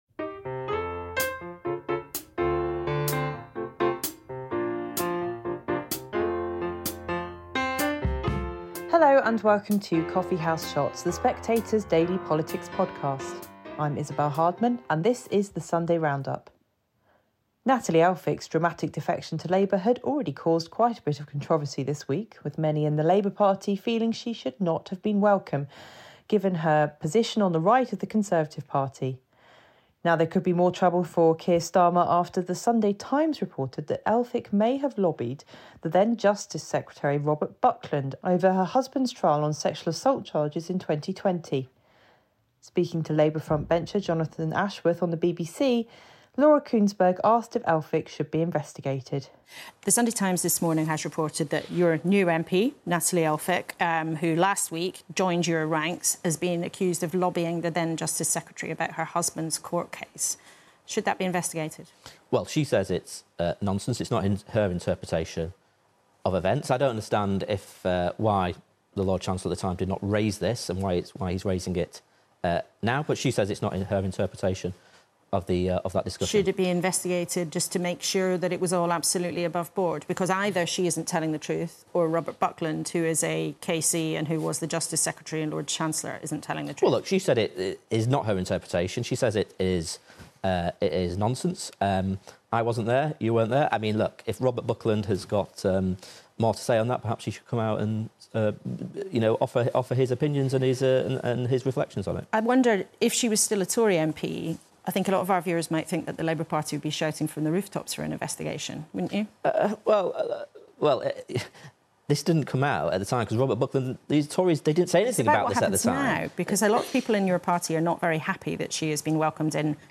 Isabel Hardman presents highlights from Sunday morning's political shows.